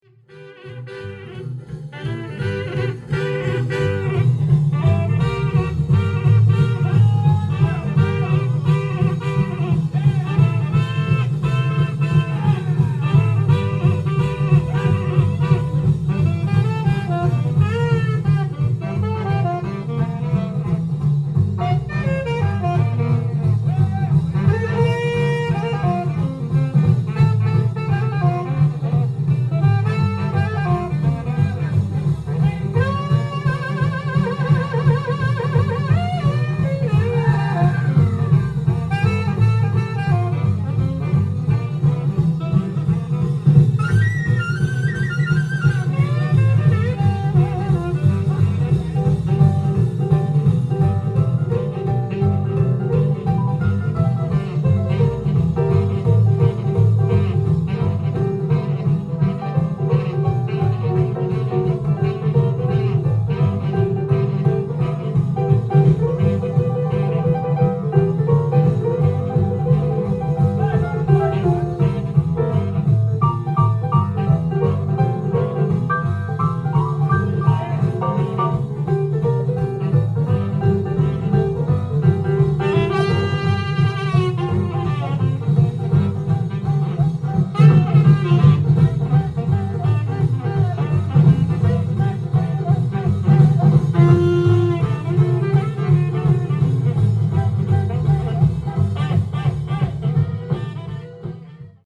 店頭で録音した音源の為、多少の外部音や音質の悪さはございますが、サンプルとしてご視聴ください。
50’s RECORDING, 未発表、別テイクも多く収録。